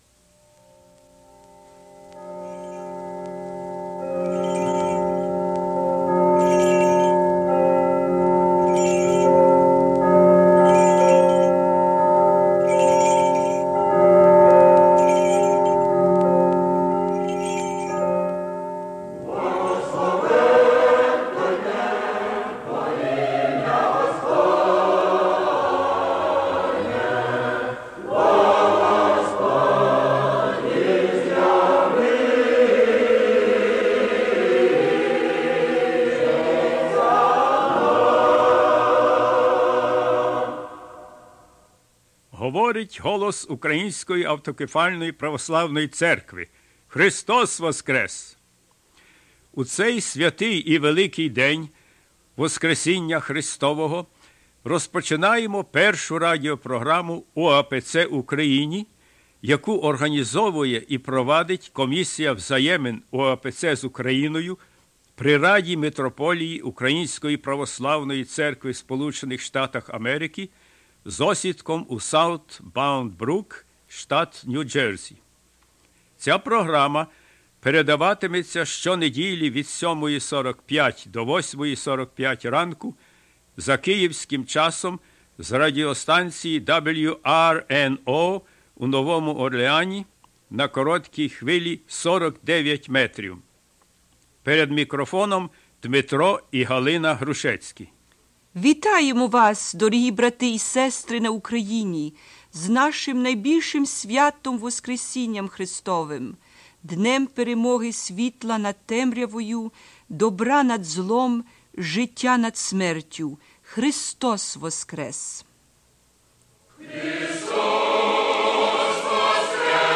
Choral rendition of "Khrystos Voskres"
Gospel reading and "spiritual discusion"
Reading of Paschal Archpastoral Letter